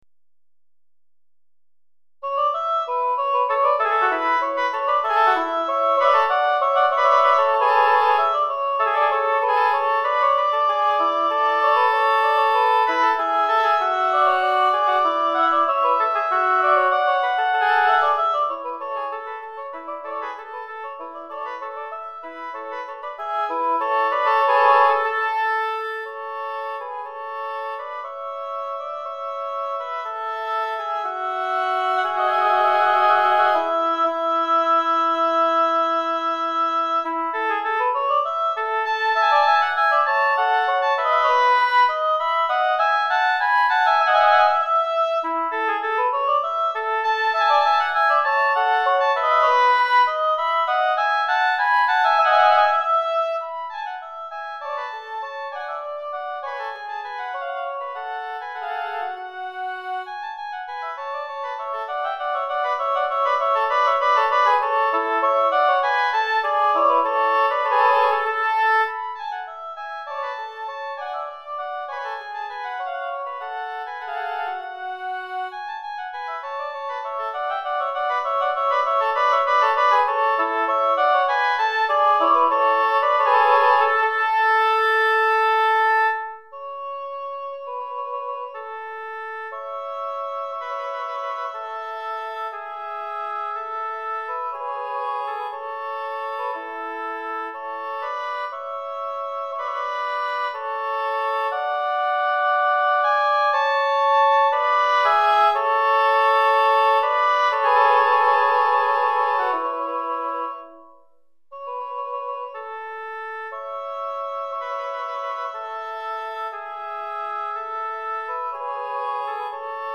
Répertoire pour Hautbois - 2 Hautbois